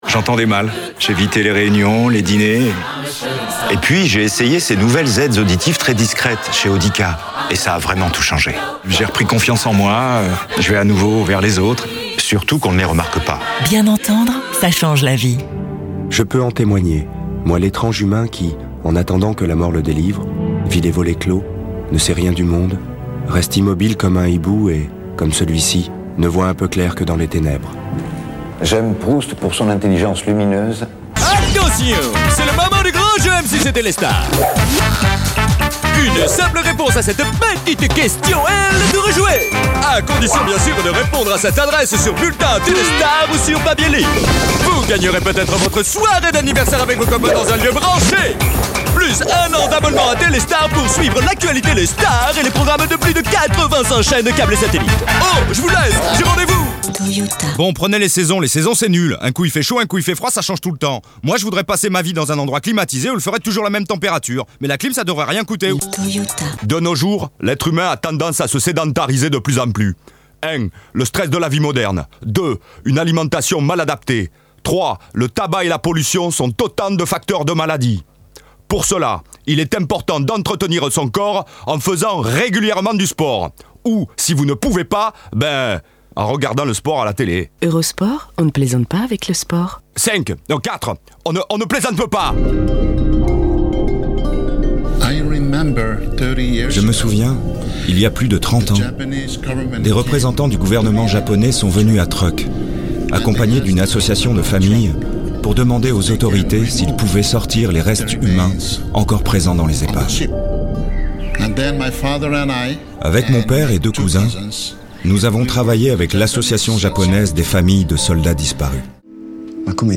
Démo vocale 1
Voix off
- Baryton-basse Baryton Ténor